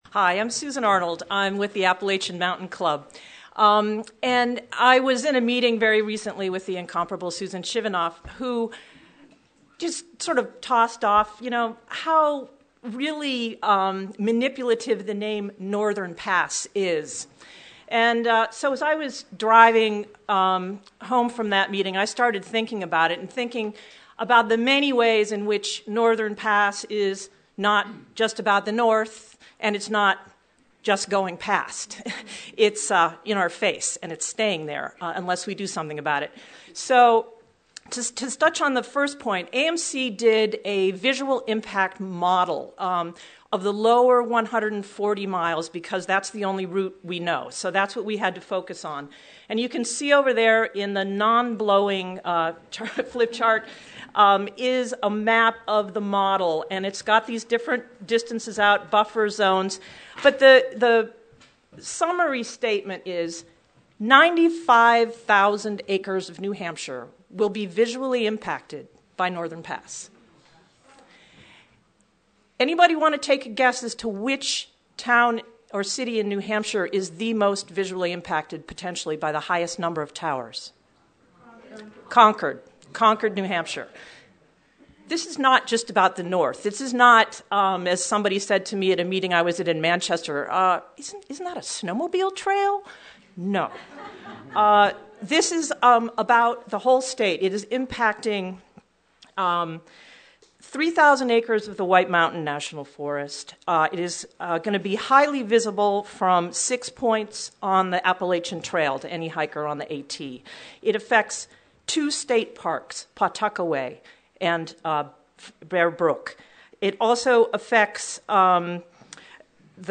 About 135 people attended a meeting of concerned citizens at the Easton Town Hall for a discussion on a broad range of topics relating to Northern Pass about the Connecticut Headwaters easement, alternatives to Northern Pass, costs of burying the line and potential revenue to the state.